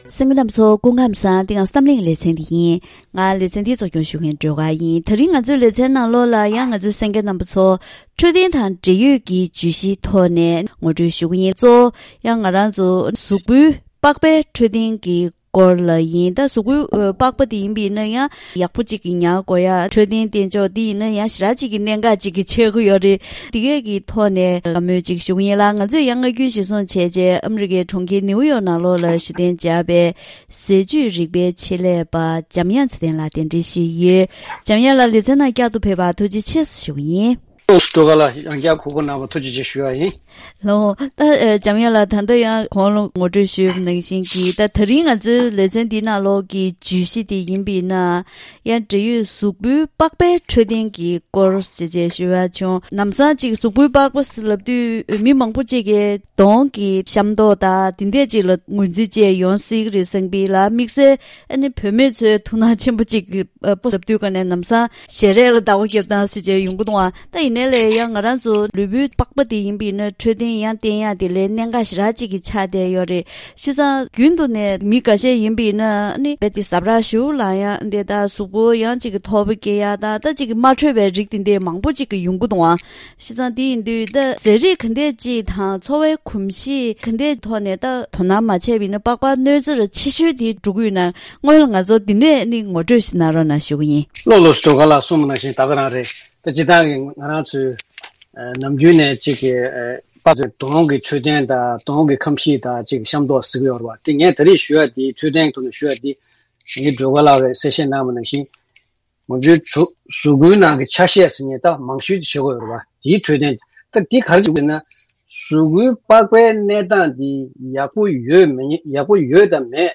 ཉིན་རེའི་འཚོ་བའི་ནང་ཟས་རིགས་དང་གོམས་གཤིས་ཐོག་ནས་པགས་པར་སྲུང་སྐྱོབ་བྱེད་སྟངས་སྐོར་ཟས་བཅུད་རིག་པའི་ཆེད་ལས་པས་ངོ་སྤྲོད་གནང་བ།